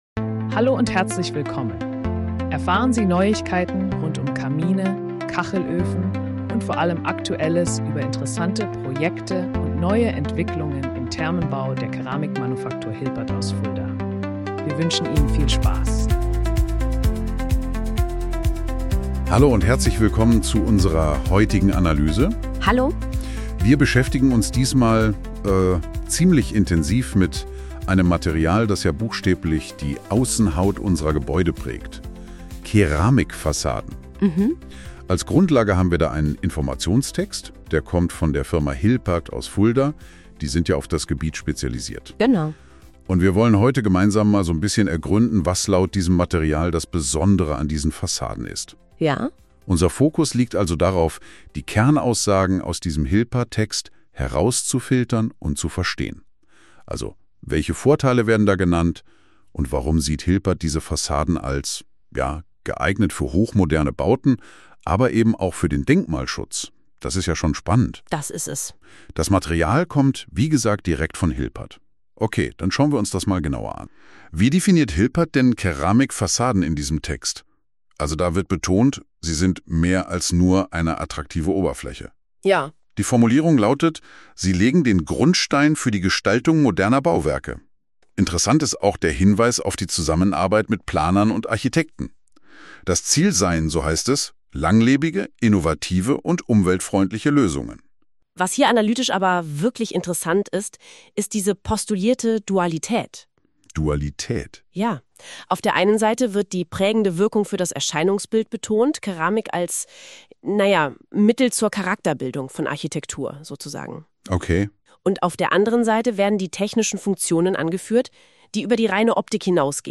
(Erstellt mit Verwendung verschiedener KI-Tools | Bookmark: Spotify)